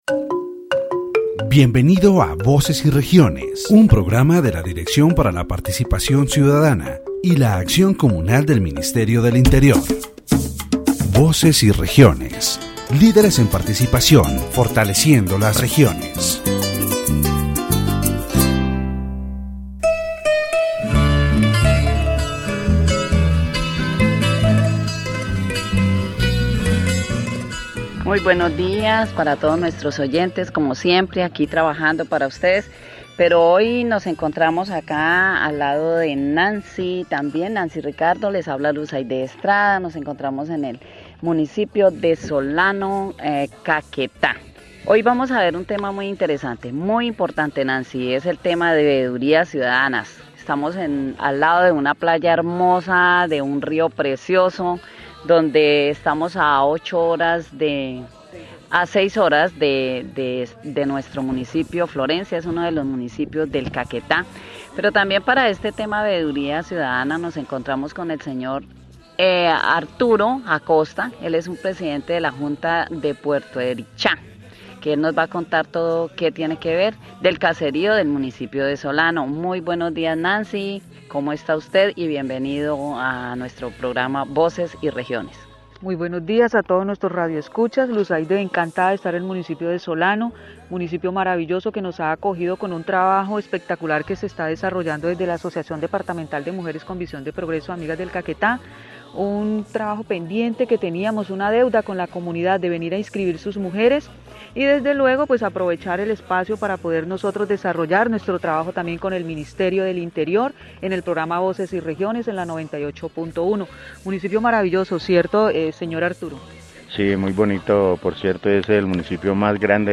The radio program "Voces y Regiones" of the Ministry of the Interior is broadcast from Solano, Caquetá, on station 98.1. In this episode, the topic of Citizen Oversight and community participation in the supervision of public projects is addressed.